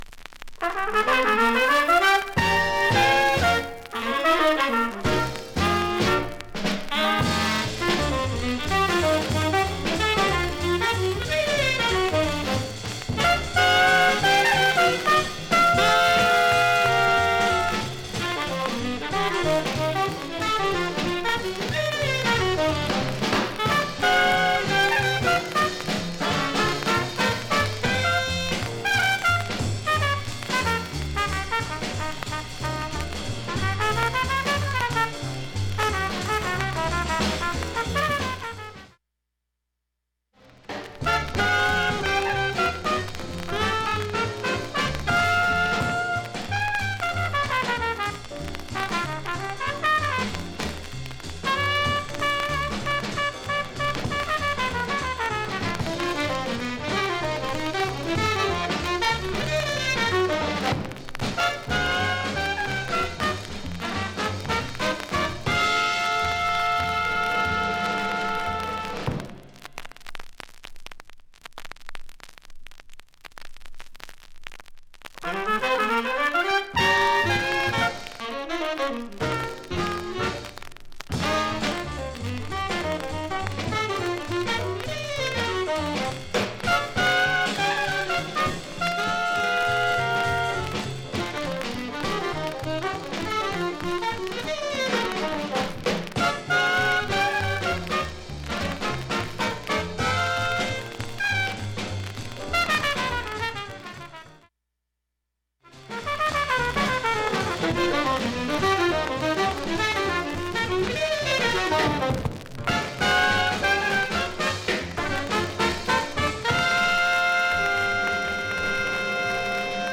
その箇所軽いノイズ出ます。
SHURE M 44G 針圧３グラムで 針飛びはありませんでした。